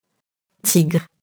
tigre [tigr]